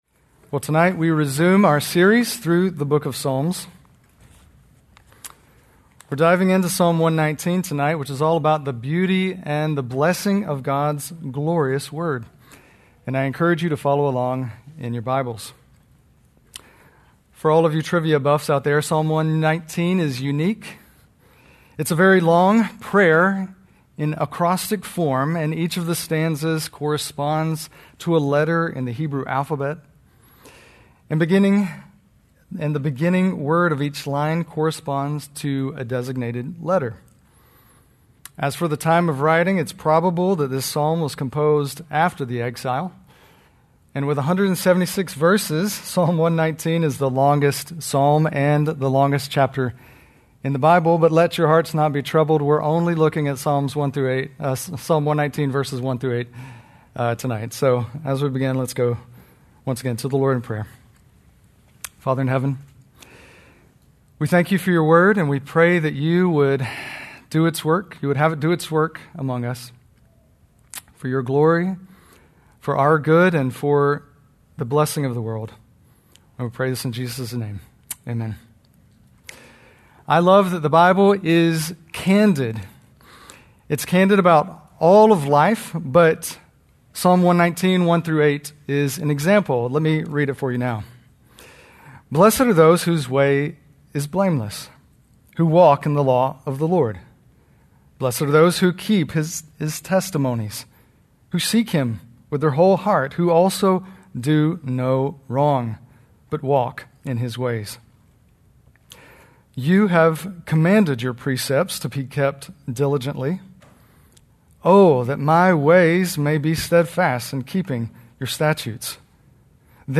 Is there a narcissist in your life that has you feeling weary and worn out? Biblical counselor